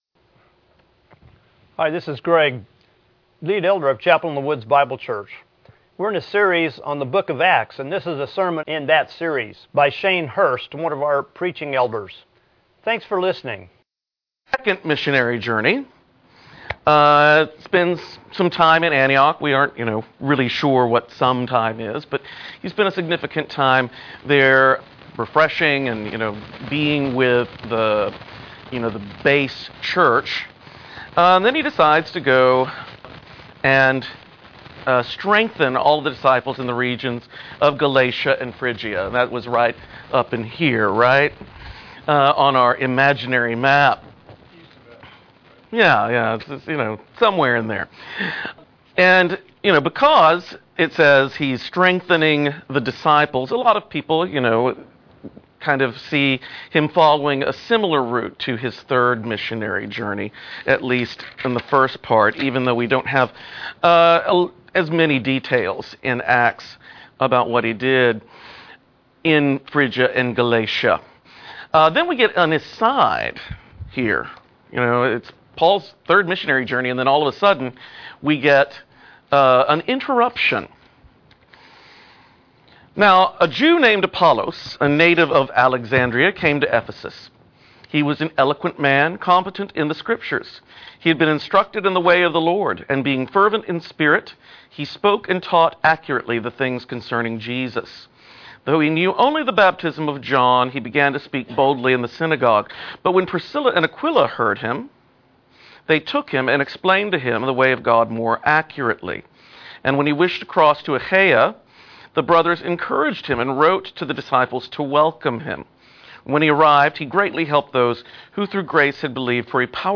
Jul 01, 2018 Acts 18:23-19:10 3rd Missions Trip MP3 SUBSCRIBE on iTunes(Podcast) Notes Discussion Sermons in this Series Beginning of the third Missionary Journey.